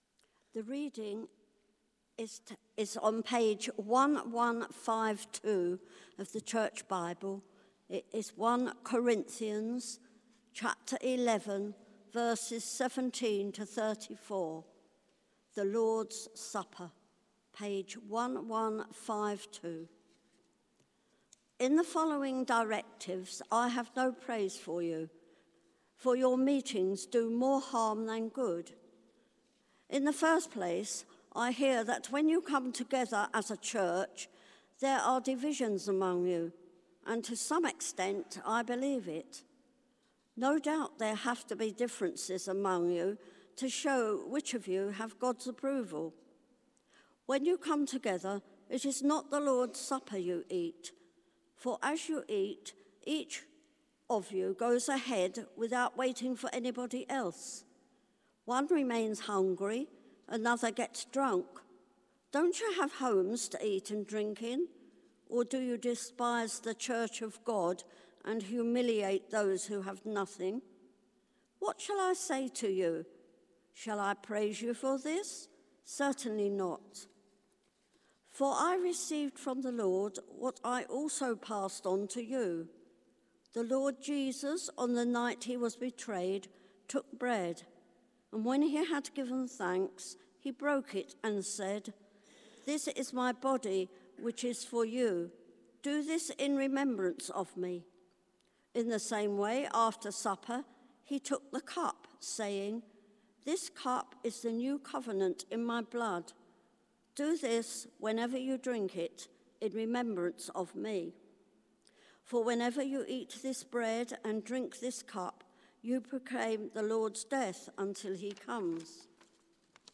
Series: God's Grace for the Christian Life Theme: The Gift of the Bread and Wine Sermon